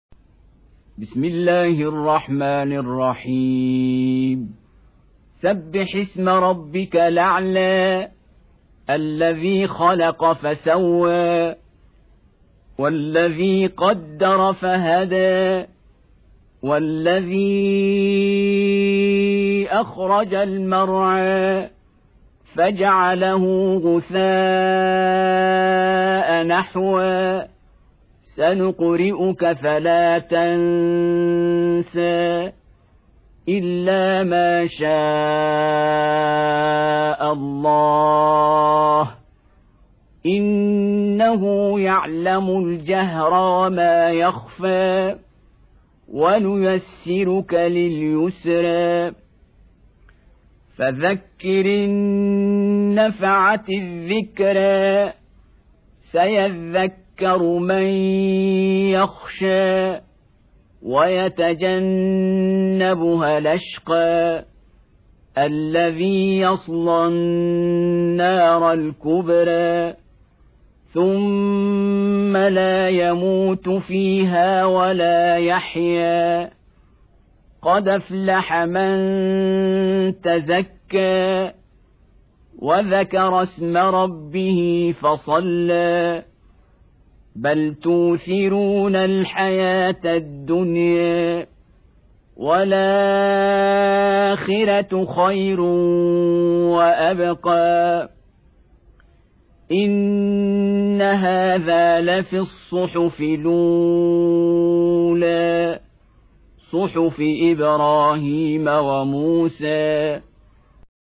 87. Surah Al-A'l� سورة الأعلى Audio Quran Tarteel Recitation
Surah Repeating تكرار السورة Download Surah حمّل السورة Reciting Murattalah Audio for 87. Surah Al-A'l� سورة الأعلى N.B *Surah Includes Al-Basmalah Reciters Sequents تتابع التلاوات Reciters Repeats تكرار التلاوات